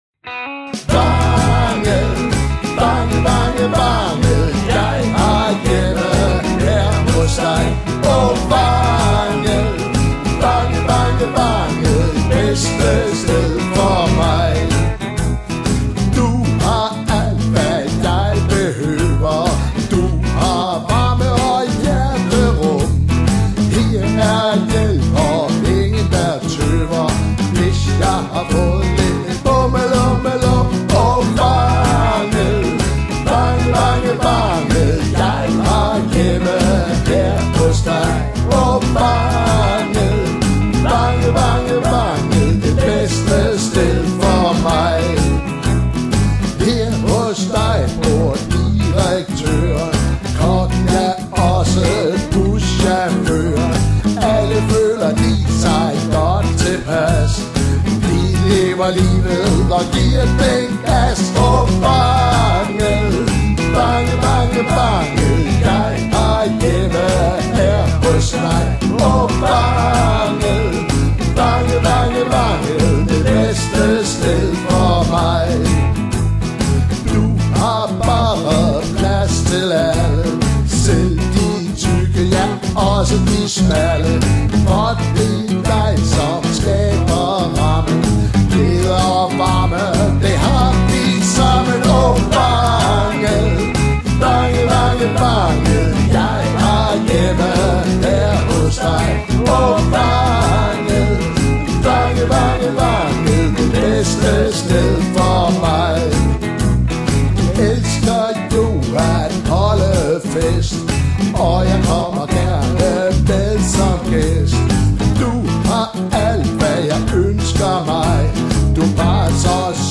festlig nummer
Lead guitar og kor
Bas og kor